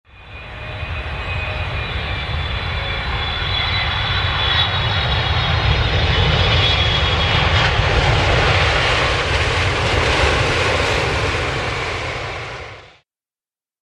ダイナミックな迫力を体感！空港に着陸する飛行機の音 着信音
空港に着陸するときの飛行機のジェット音。臨場感あふれるダイナミックなサウンドです。映画やドラマの効果音として、あるいは旅行の思い出を振り返る際などに最適な素材です。